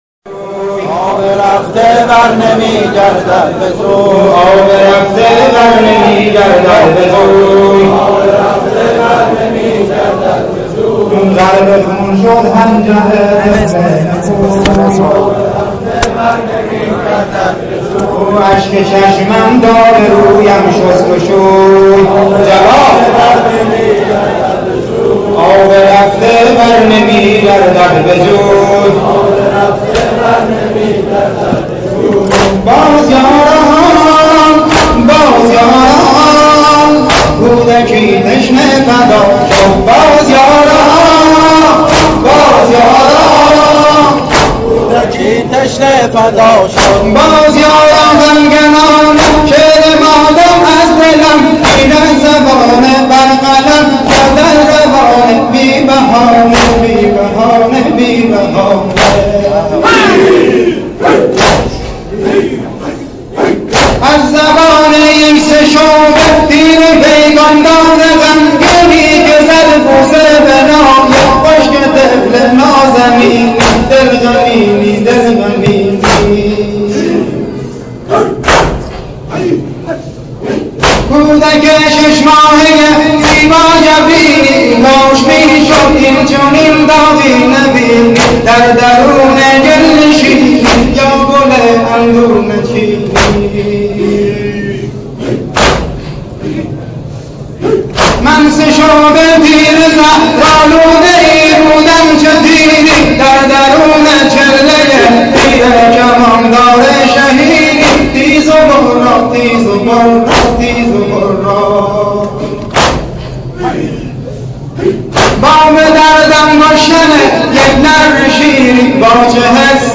متن نوحه بحرالطویل " تیرِ سه شعبه " زبان حال تیری که به حنجر طفل شش ماهه اباعبداله نشسته است :